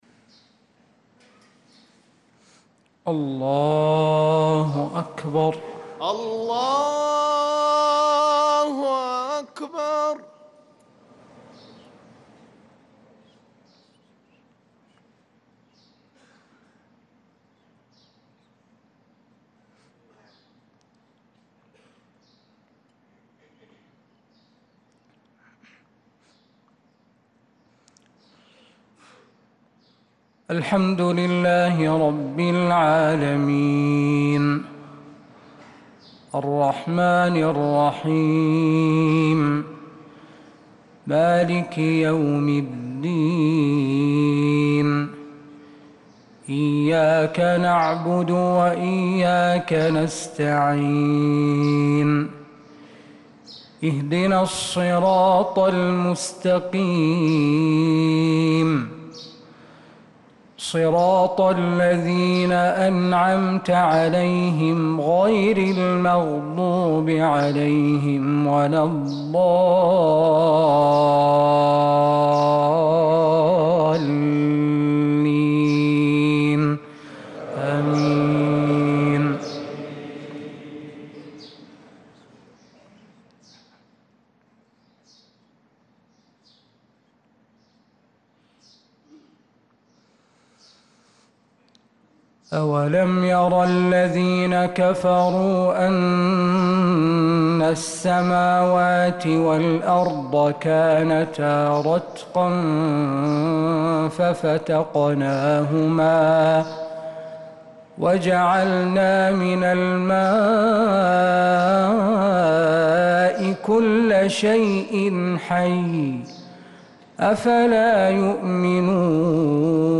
صلاة الفجر
تِلَاوَات الْحَرَمَيْن .